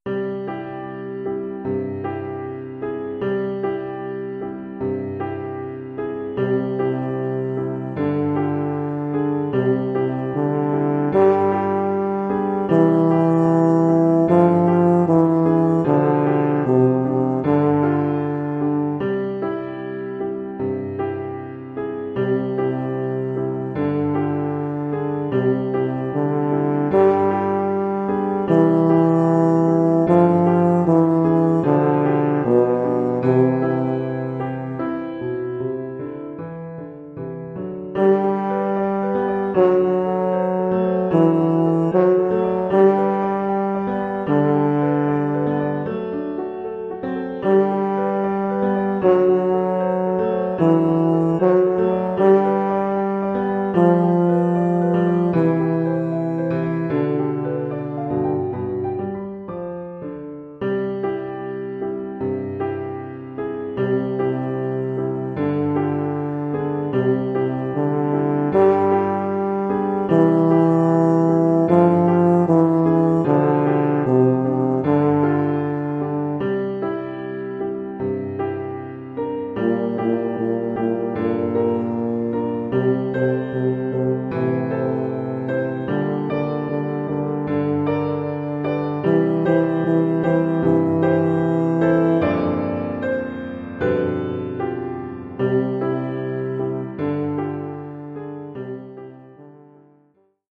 Oeuvre pour saxhorn basse et piano.
Oeuvre pour saxhorn basse / euphonium /
tuba et piano.